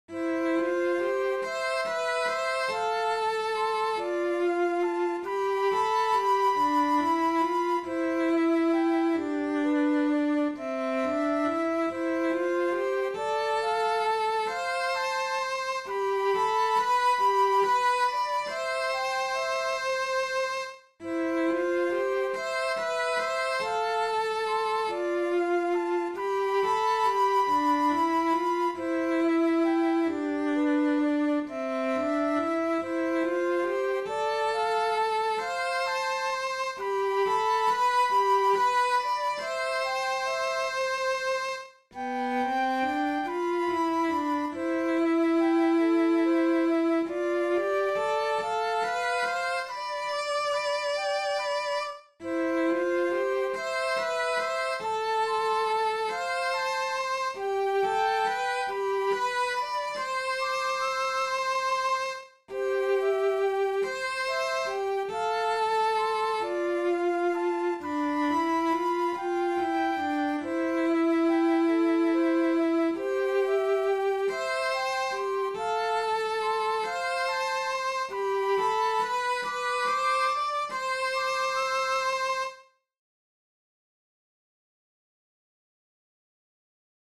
Ylitse-pimean-maan-sello-ja-huilu.mp3